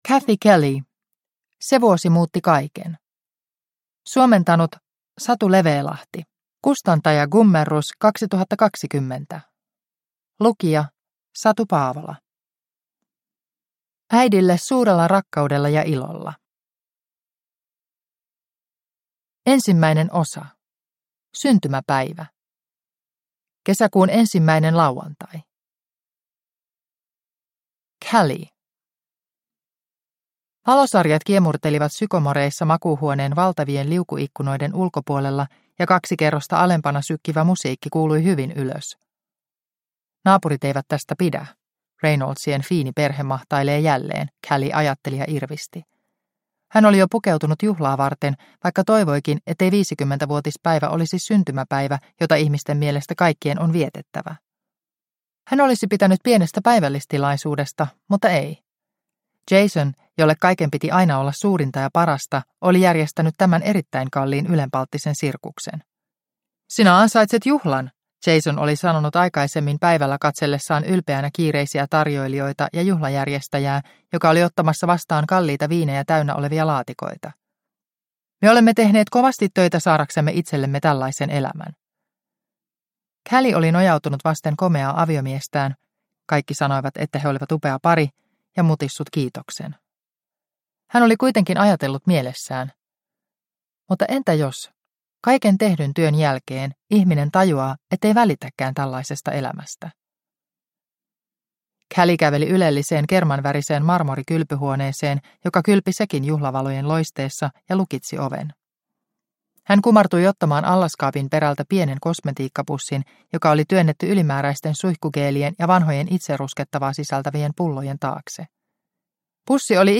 Se vuosi muutti kaiken – Ljudbok – Laddas ner